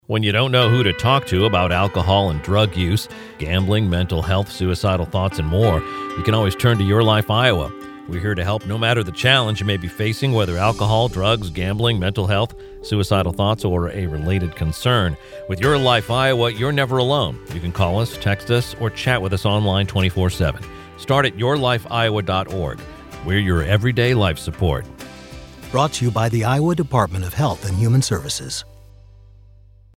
:30 Radio Spot | YLI Awareness (Male-1)